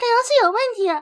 Techmino/media/effect/chiptune/error.ogg at deb8c09c22e6220a0ea9ca799bfafd2dfc8a1f4a
error.ogg